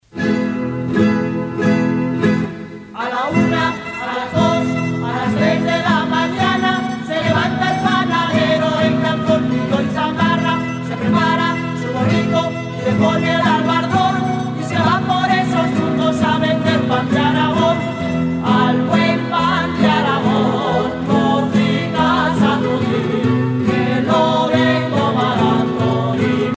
danse : jota (Espagne)
Aragonés (Grupo folclorico)
Pièce musicale éditée